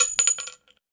weapon_ammo_drop_10.wav